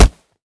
kick3.wav